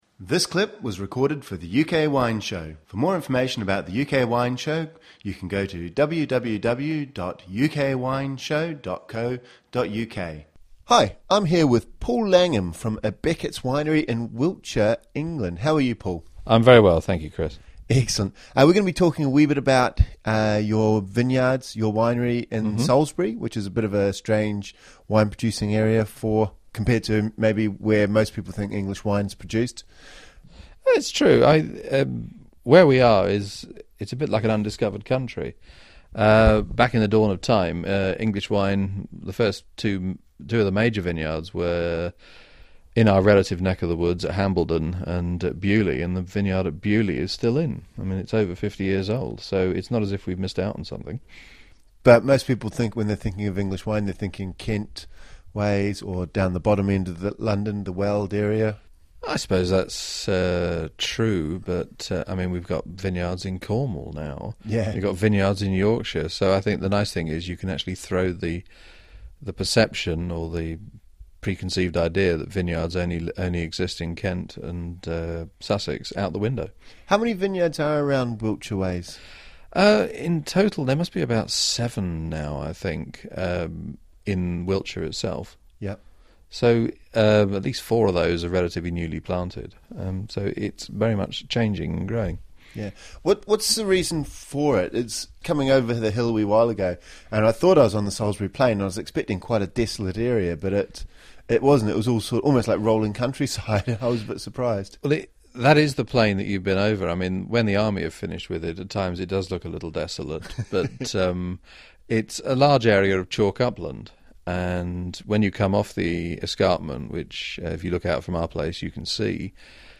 » Listen to the full UK Wine Show